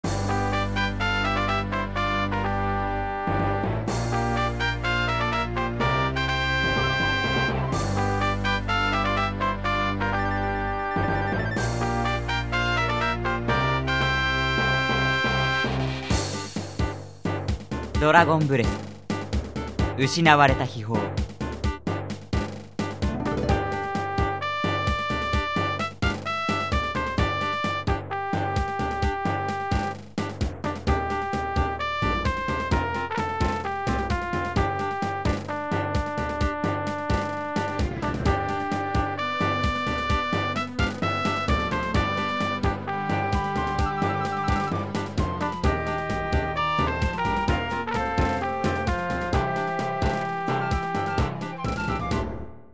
ボイスドラマ 登場キャラクター 試聴時間 容量